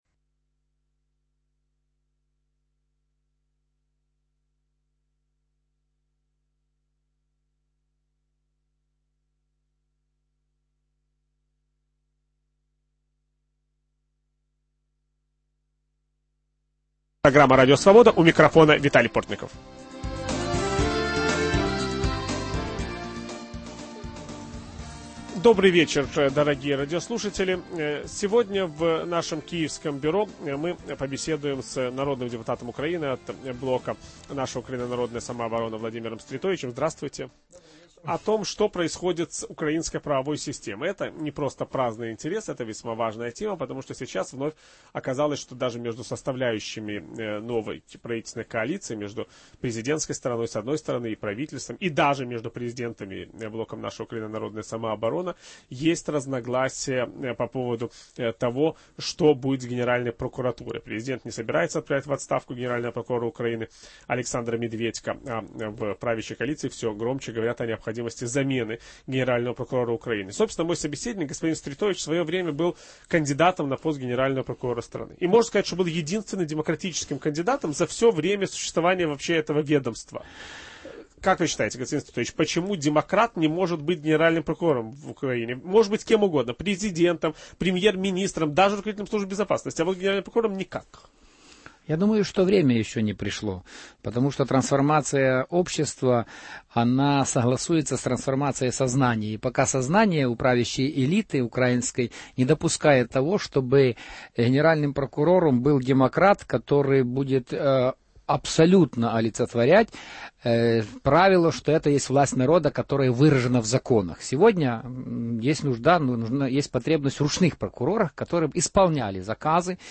О проблемах в украинской правовой системе ведущий программы Виталий Портников беседует с депутатом Верховной Рады Украины Владимиром Стретовичем